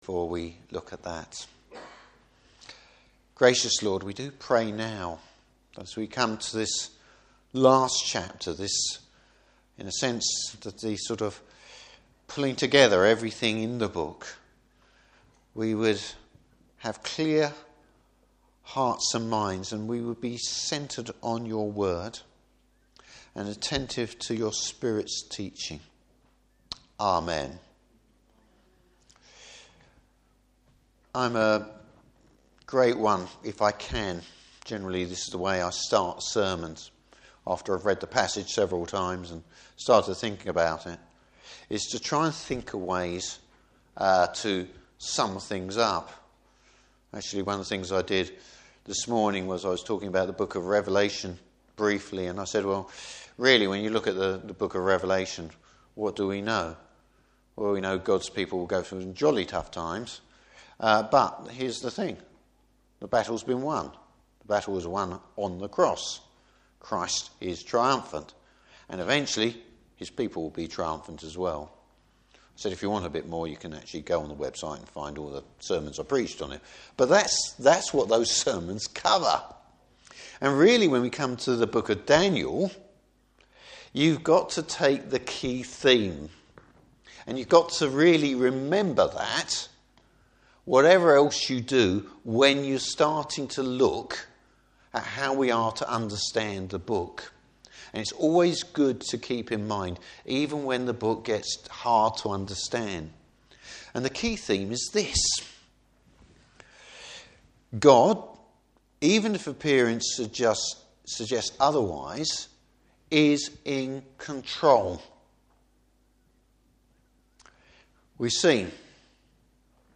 Service Type: Evening Service The Church may faced difficult times.